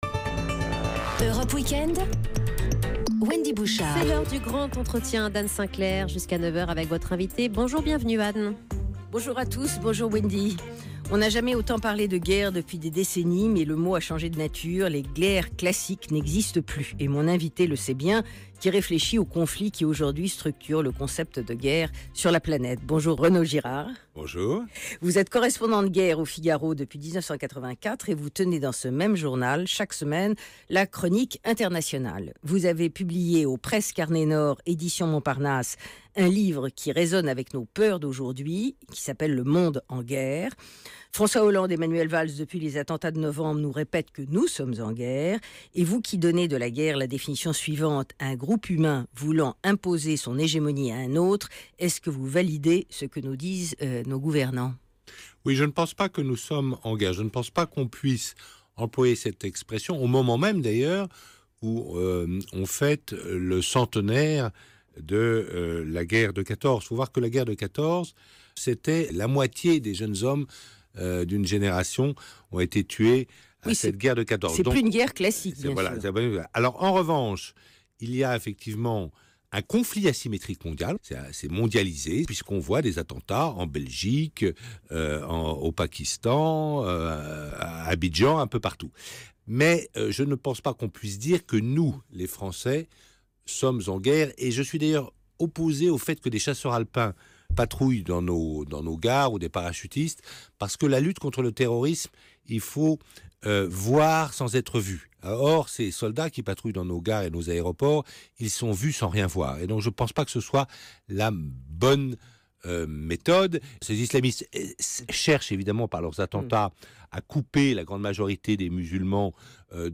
Renaud Girard, l’interview intégrale sur Europe 1
renaud-girard-linterview-intc3a9grale.mp3